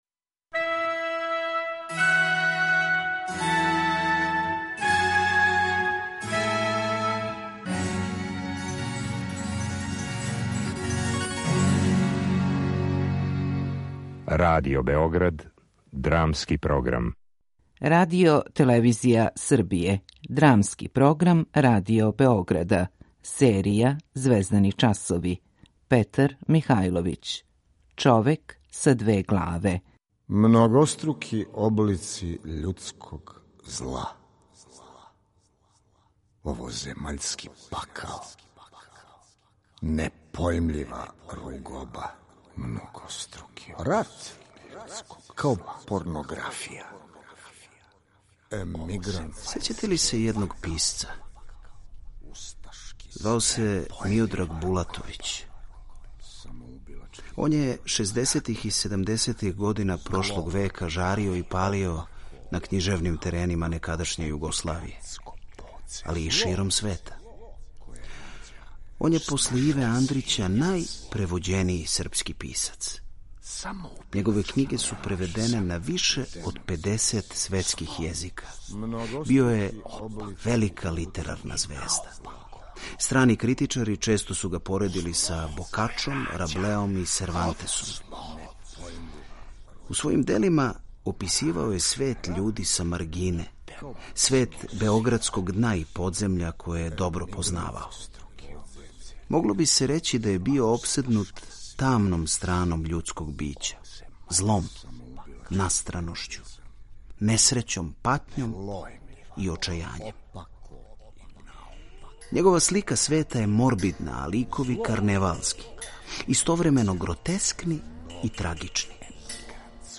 Драмски програм